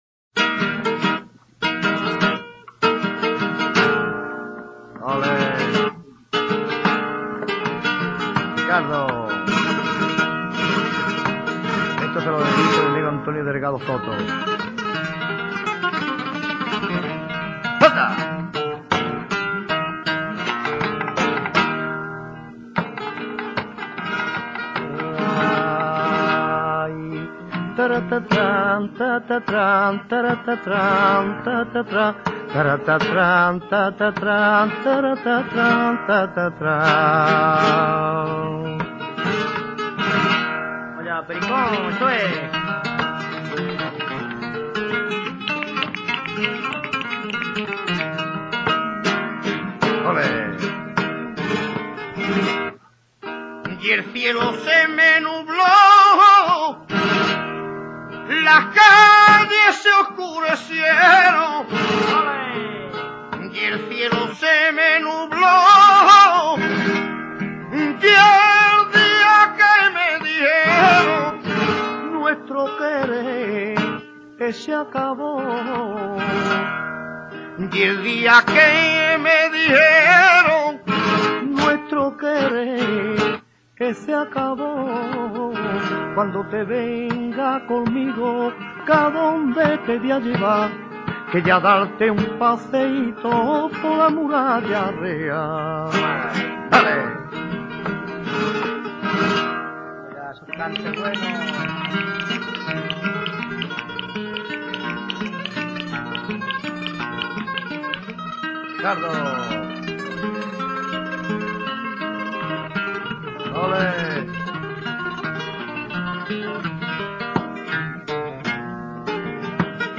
Sonidos y Palos del Flamenco
cantina.mp3